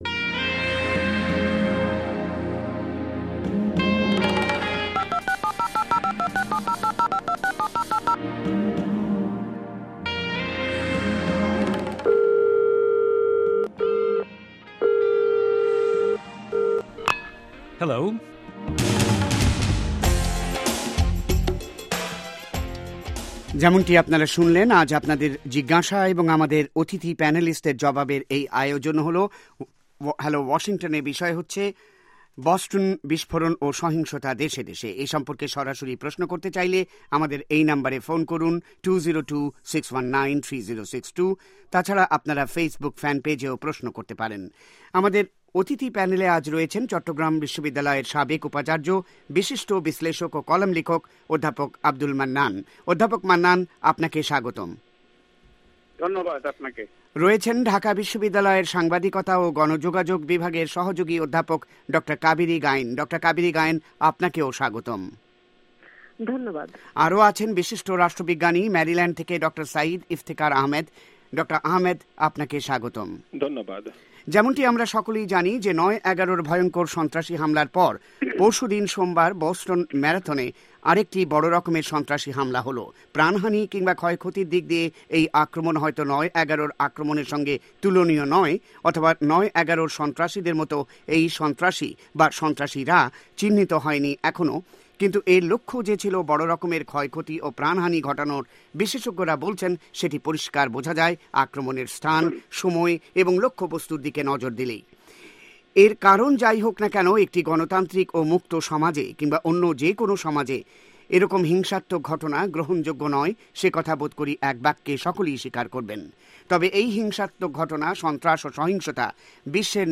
শুনুন কল ইন শো